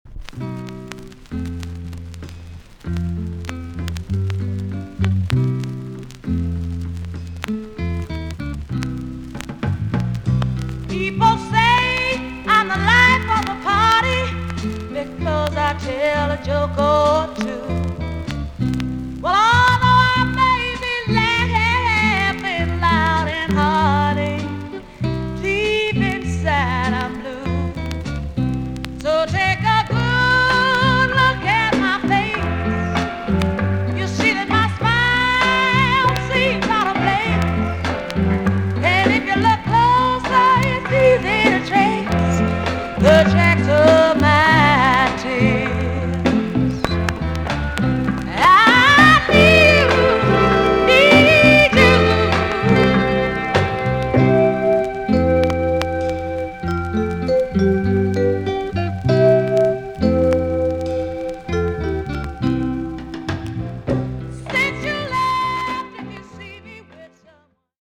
TOP >JAMAICAN SOUL & etc
VG+ 少し軽いチリノイズがあります。